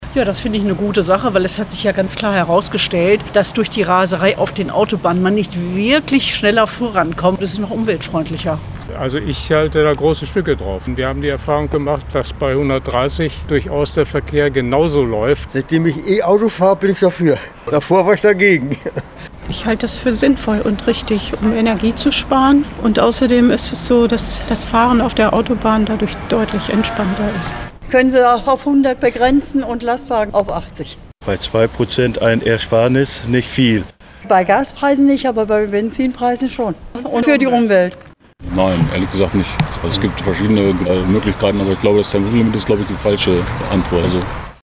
Landkreis Hameln-Pyrmont: UMFRAGE TEMPOLIMIT
landkreis-hameln-pyrmont-umfrage-tempolimit.mp3